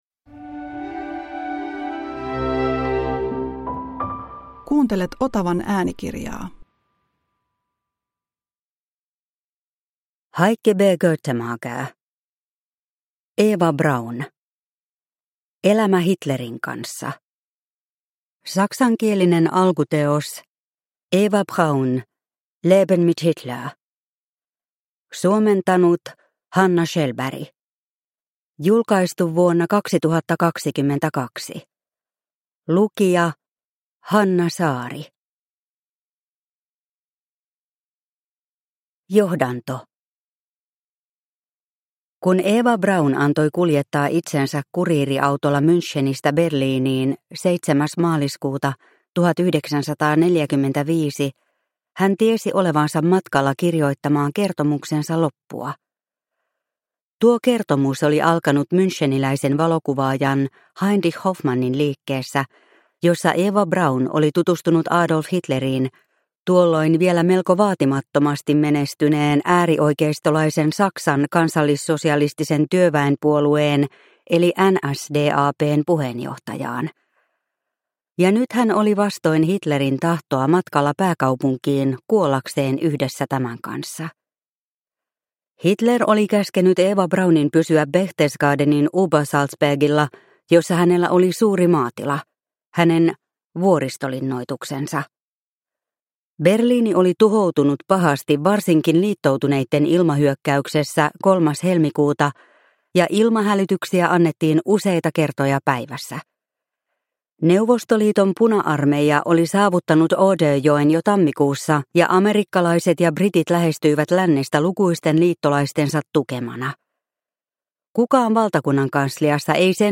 Eva Braun – Ljudbok – Laddas ner